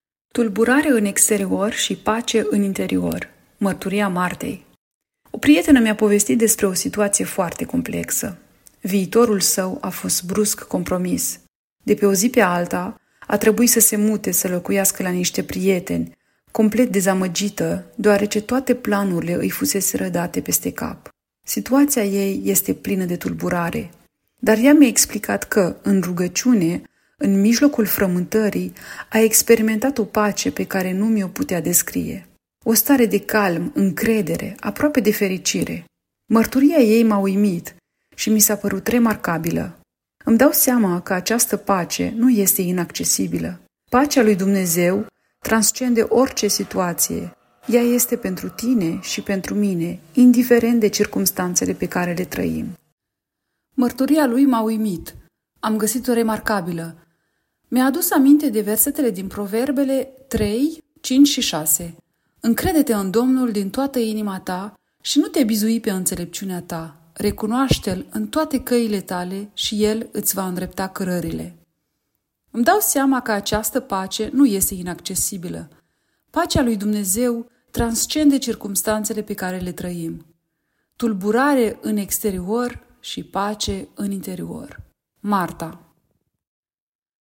Audio, Mărturii